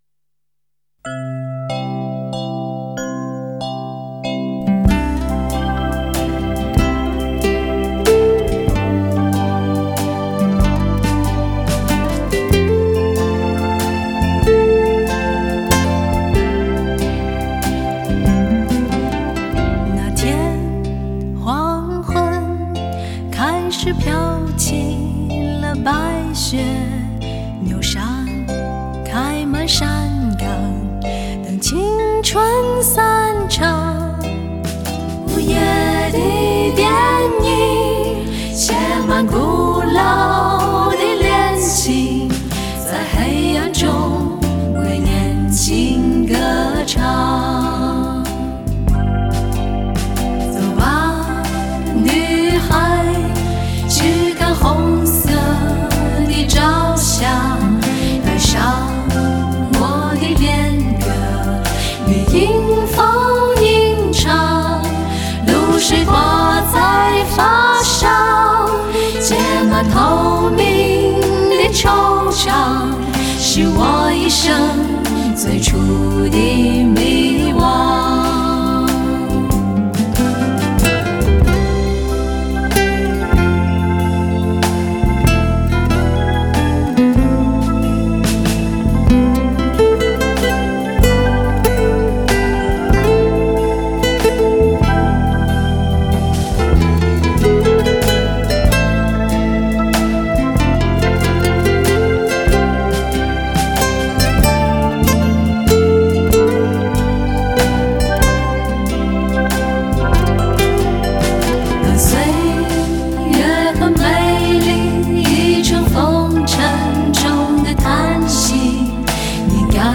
如何寻找一份寂静与解脱……清纯、甜美的歌声，